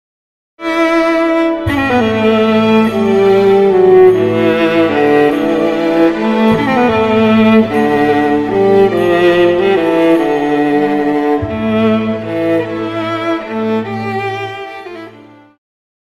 Pop
Viola
Orchestra
Instrumental
Only backing